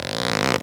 foley_leather_stretch_couch_chair_18.wav